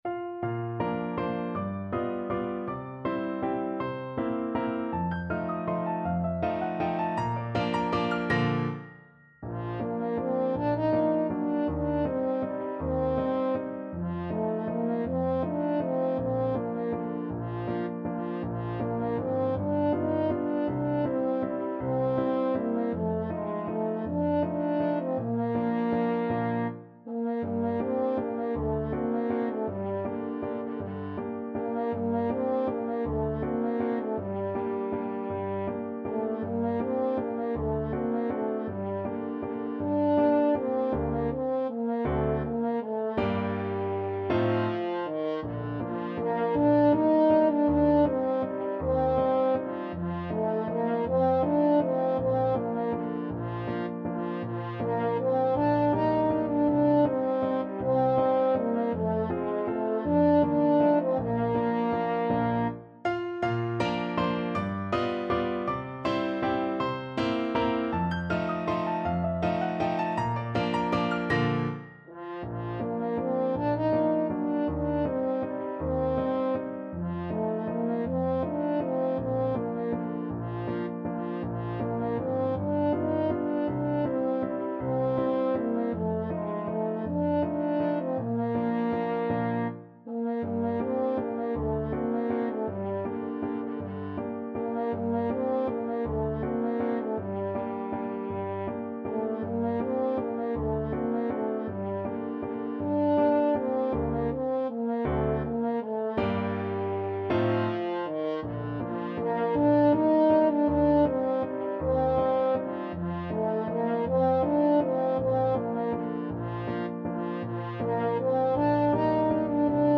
French Horn
Bb major (Sounding Pitch) F major (French Horn in F) (View more Bb major Music for French Horn )
3/4 (View more 3/4 Music)
~ = 160 Tempo di Valse
Traditional (View more Traditional French Horn Music)